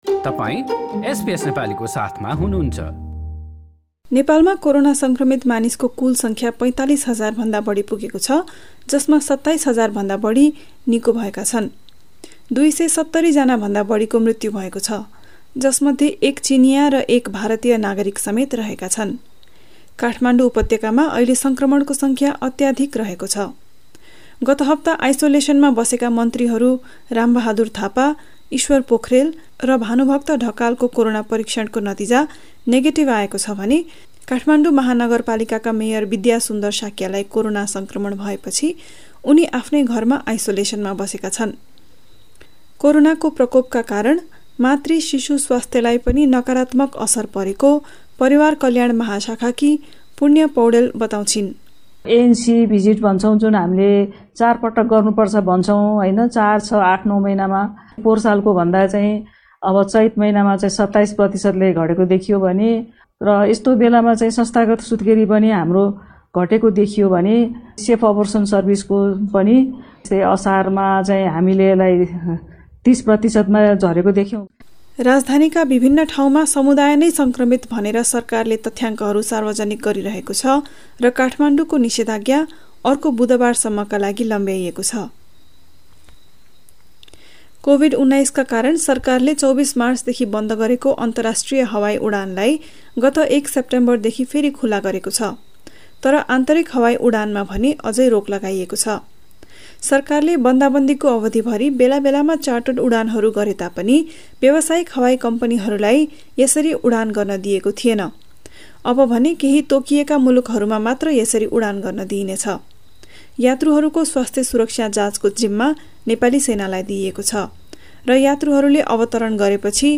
A text version of this news report is available in the Nepali language version of our website.